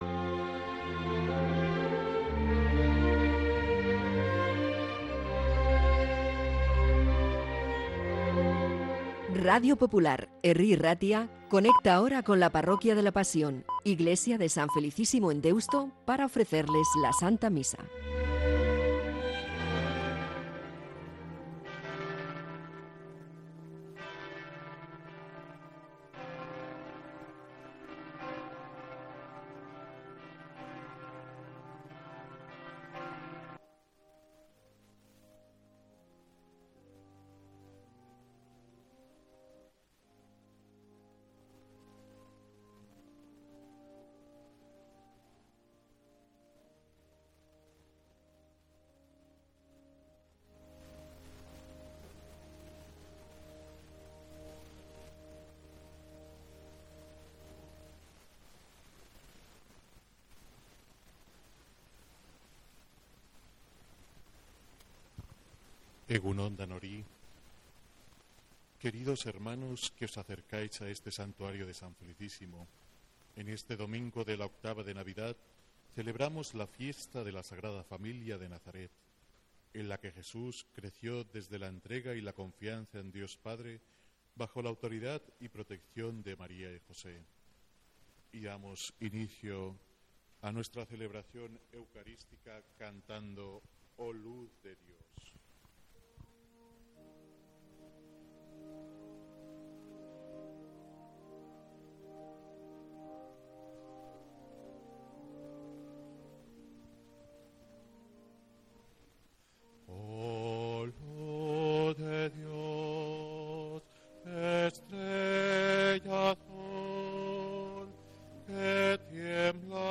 Santa Misa desde San Felicísimo en Deusto, domingo 29 de diciembre